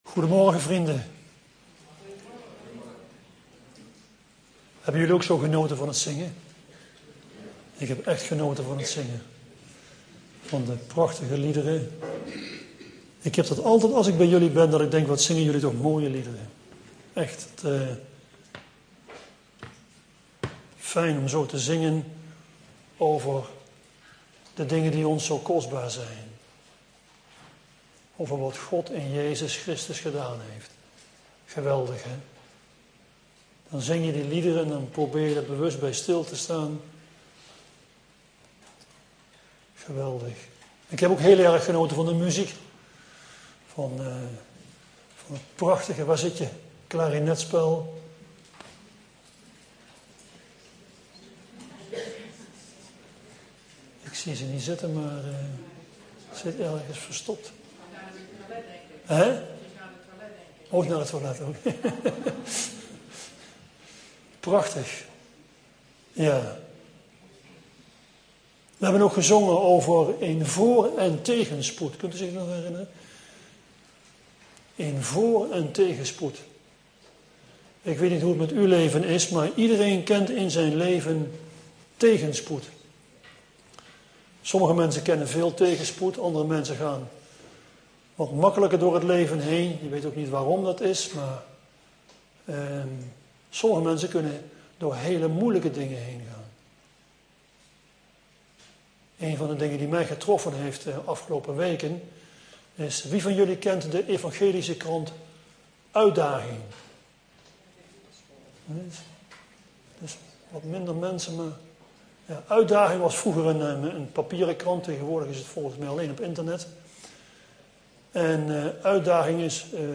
AfspelenDownloadAudiobestand (MP3) Powerpoint bij de preek